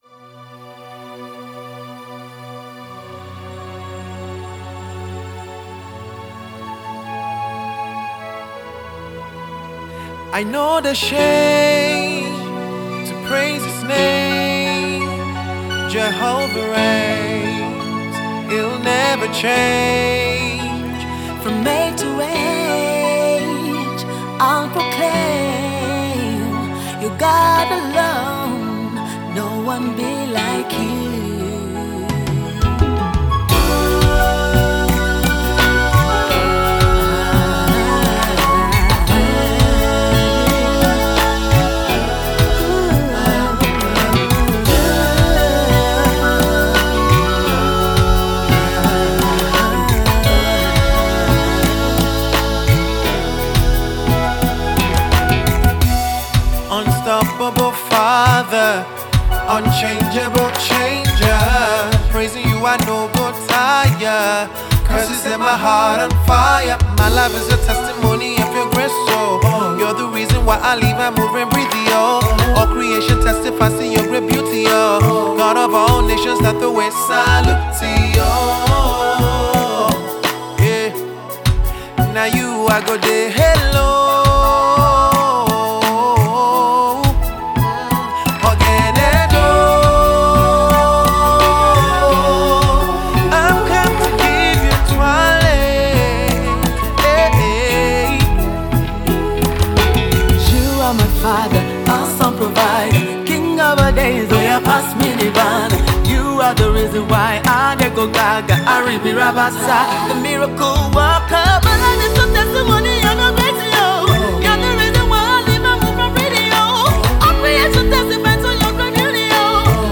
song of adoration